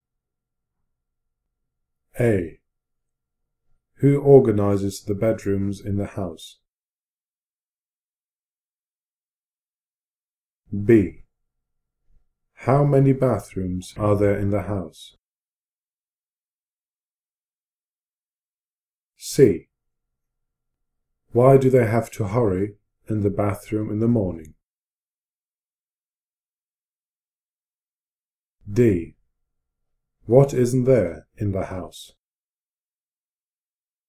GCSE German revision tool with 148 spoken questions and answers on CDs.
Written and recorded by a native speaker